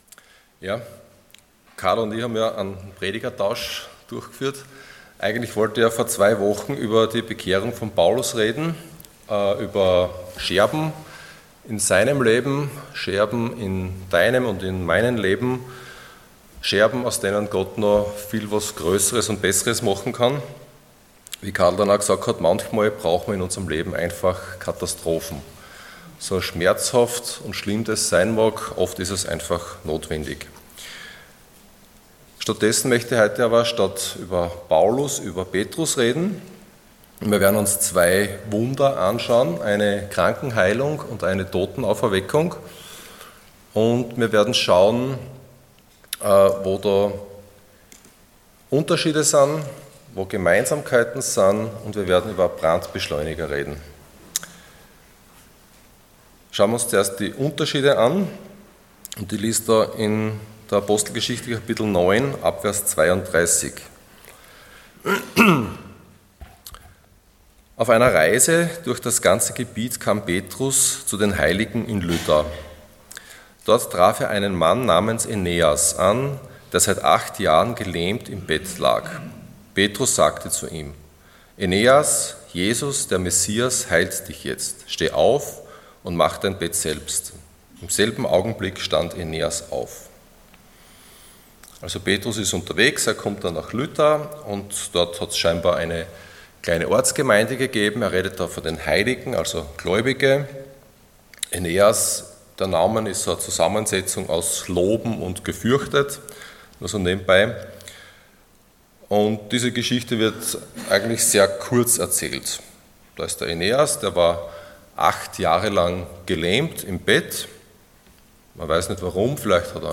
Ihr werdet meine Zeugen sein Passage: Acts 9:32-43 Dienstart: Sonntag Morgen %todo_render% Brandbeschleuniger « Neue Gefahren